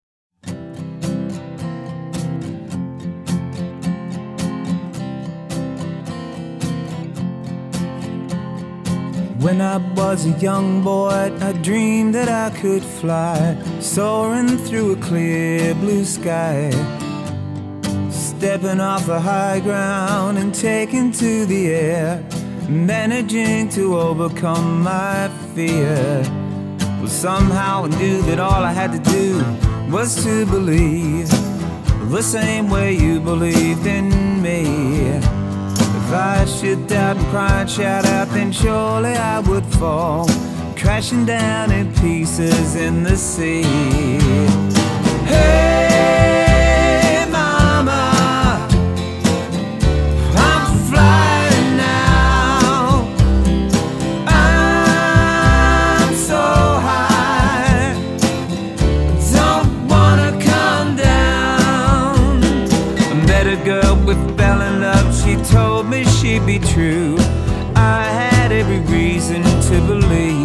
★ 來自澳洲的民謠爵士三人組清新自然的民謠演繹，帶給您舒服暢快的聆聽享受！